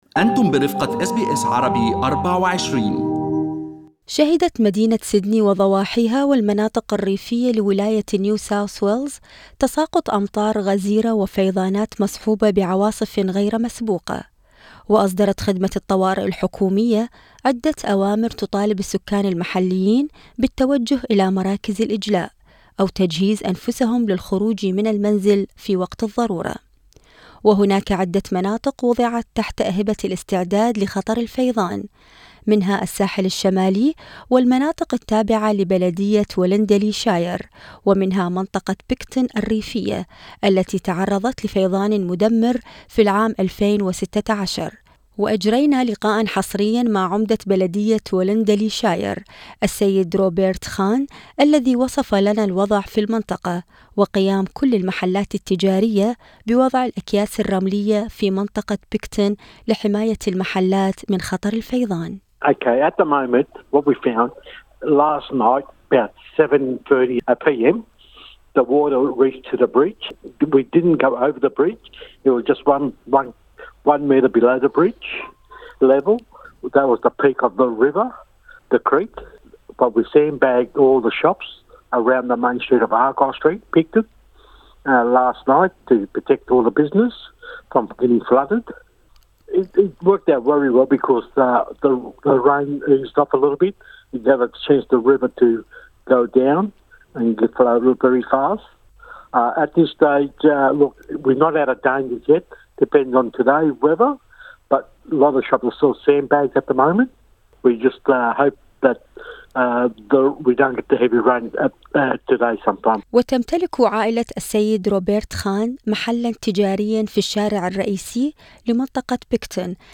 في لقاءٍ حصري: العمدة روبيرت خان يصف خوف السكان المتضررين بالفيضان في نيو ساوث ويلز
وأجرينا لقاءً حصريا مع عمدة بلدية Wollondily Shire السيد روبيرت خان الذي وصف لنا الوضع في المنطقة وقيام كل المحلات التجارية بوضع الأكياس الرملية في منطقة Picton لحماية المحلات من خطر الفيضان.